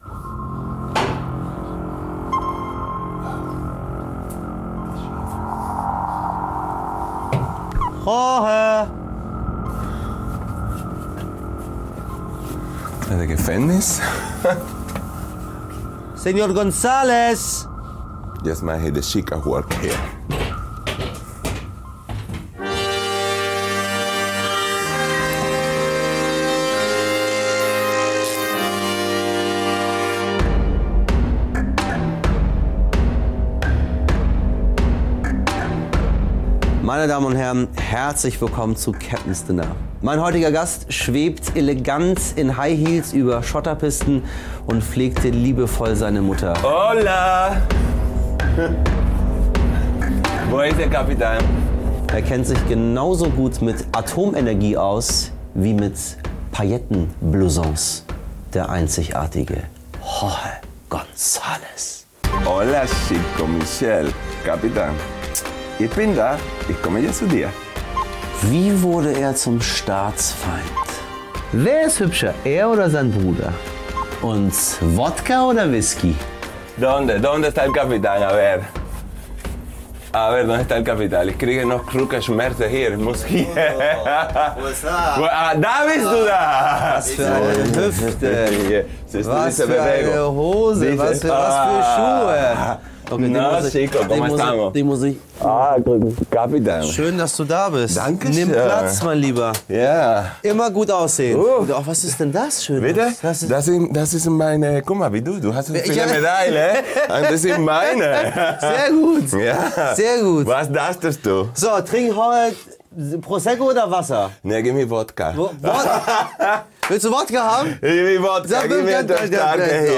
Bei Michel Abdollahi im U-Boot ist Choreograf und Model Jorge González zu Gast.
Michel Abdollahi empfängt seine Gäste in einem alten U-Boot im Hamburger Hafen. Eine halbe Stunde Talk zwischen Torpedos und Stahl, zur Lage der Nation und allem, was sonst noch wichtig ist!